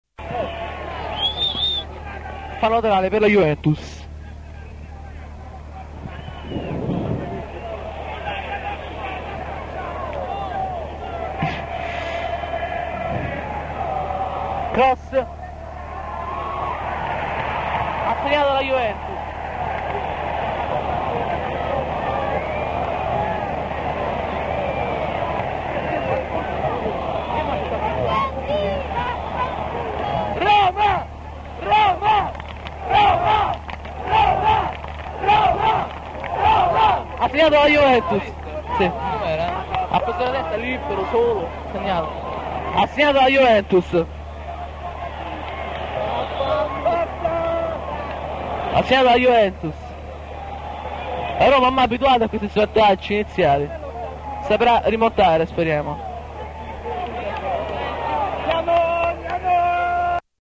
"Ti invio alcuni pezzi della cassetta che ho registrato il 13/04/1980 all'Olimpico durante Roma-Juventus 1-3.